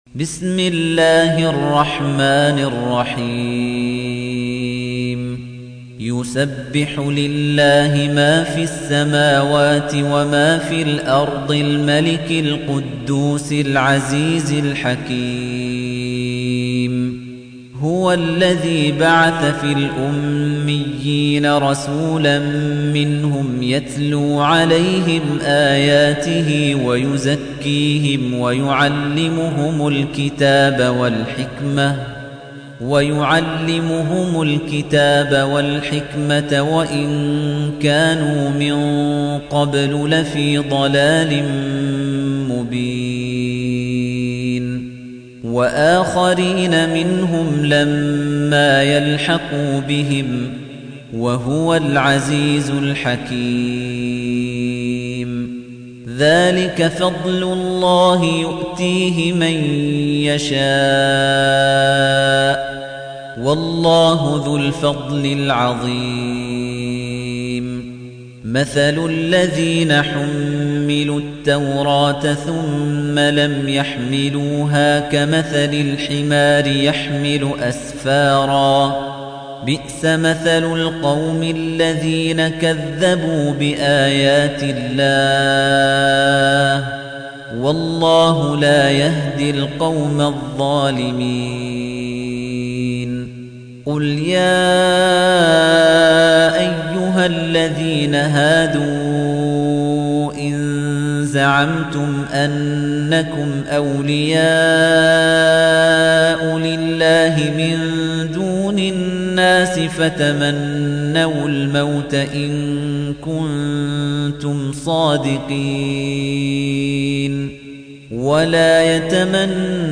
تحميل : 62. سورة الجمعة / القارئ خليفة الطنيجي / القرآن الكريم / موقع يا حسين